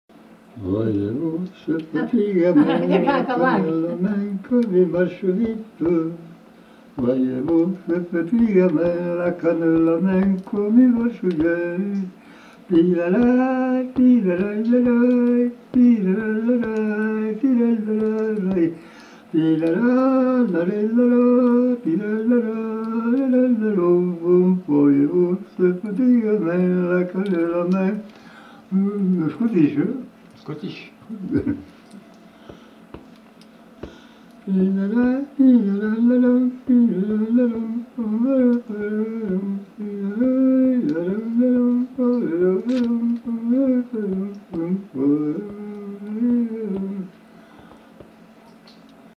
Aire culturelle : Couserans
Genre : chant
Type de voix : voix d'homme
Production du son : fredonné ; chanté
Danse : mazurka
Notes consultables : Le donne comme scottish.